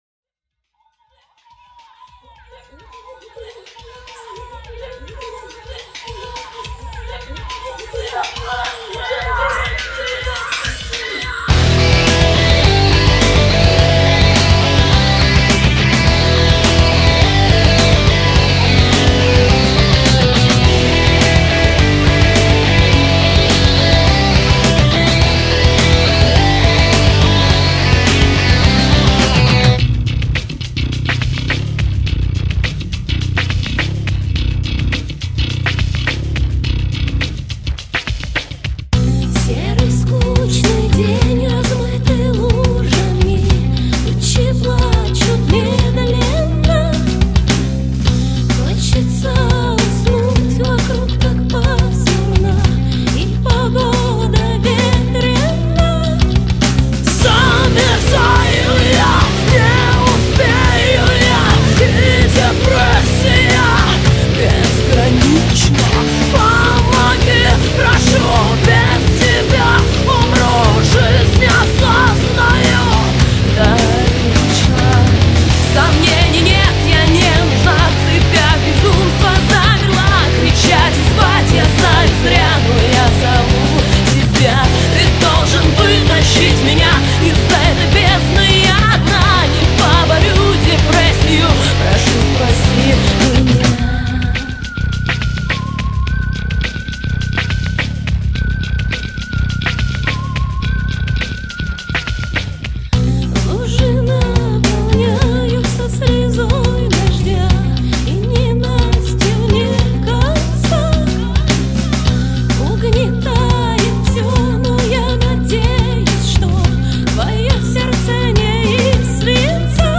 альтернатива новосиба